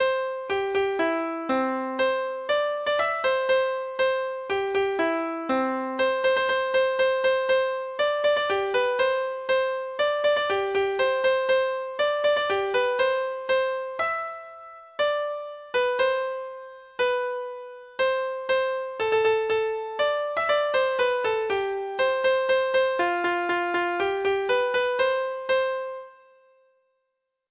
Folk Songs from 'Digital Tradition' Letter G Green Grow the Rushes, O
Free Sheet music for Treble Clef Instrument
Treble Clef Instrument  (View more Intermediate Treble Clef Instrument Music)
Traditional (View more Traditional Treble Clef Instrument Music)